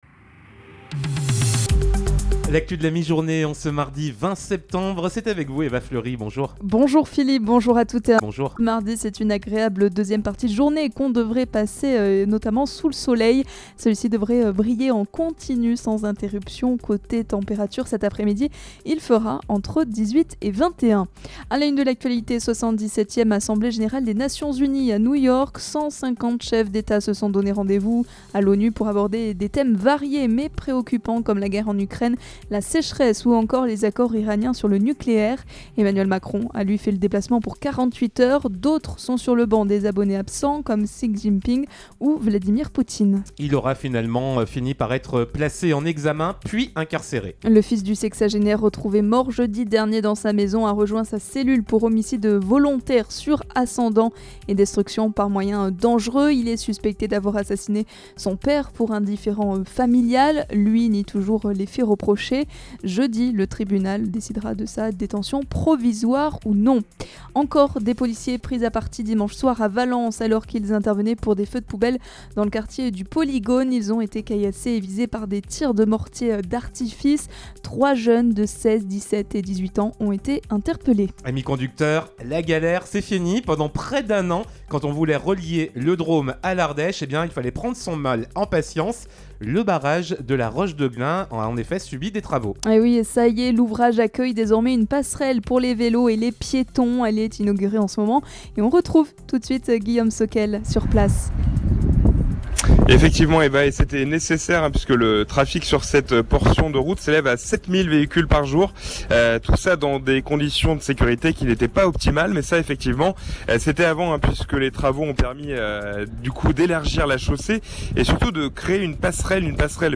Mardi 20 septembre 2022: Le journal de 12h